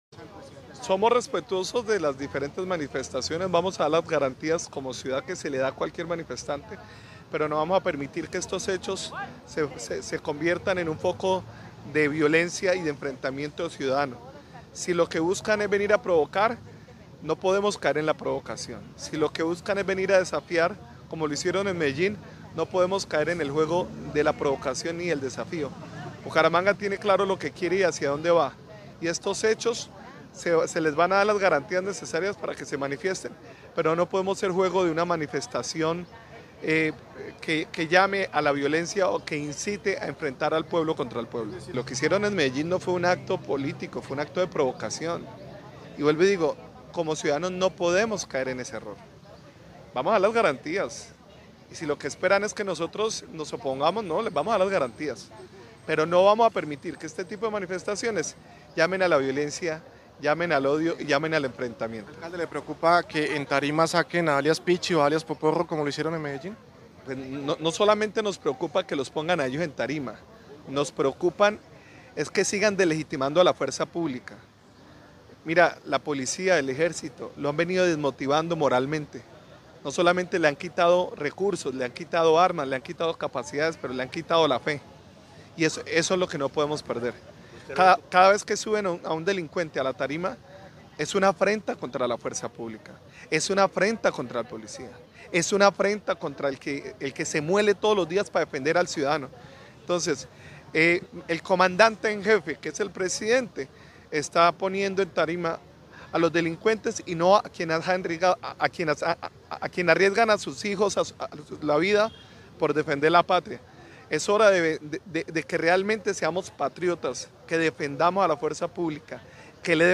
Jaime Andrés Beltrán, alcalde de Bucaramanga